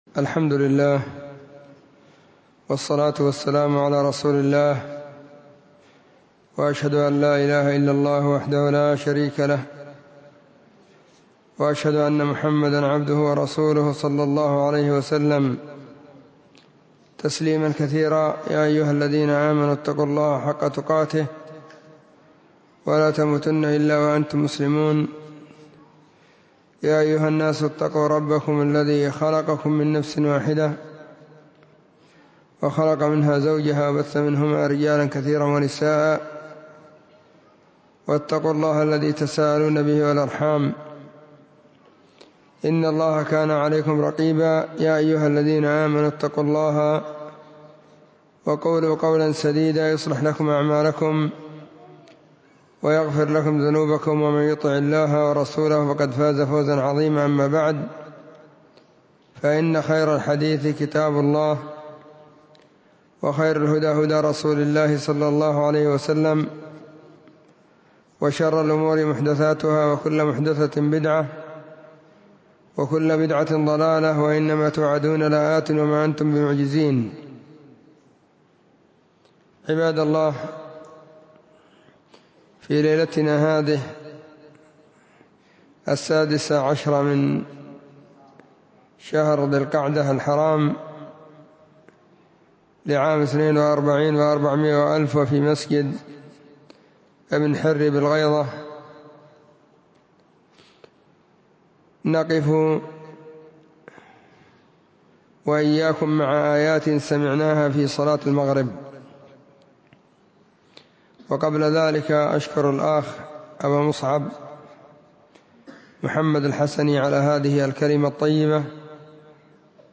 محاضرة بعنوان; *📚الإبانة في بيان معاني النصف الأخير من سورة القيامة.*
📢 مسجد الصحابة – بالغيضة – المهرة، اليمن حرسها •اللّـہ.